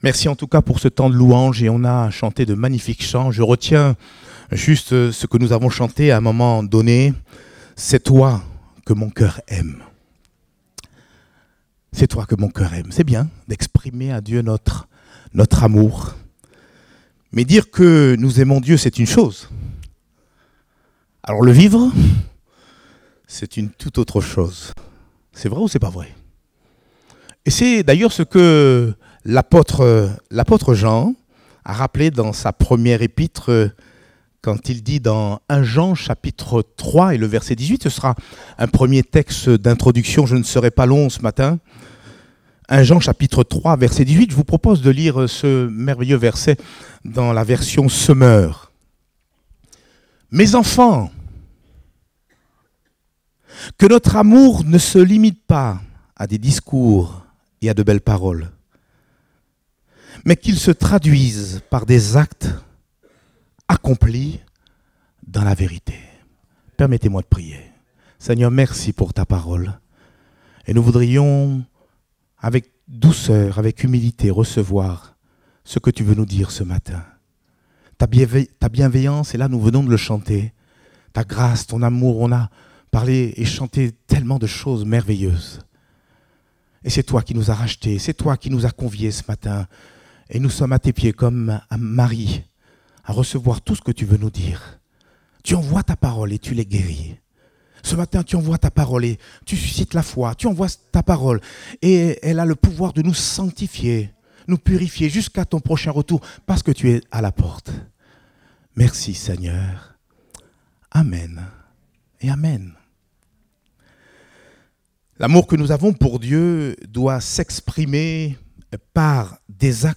Date : 12 novembre 2023 (Culte Dominical)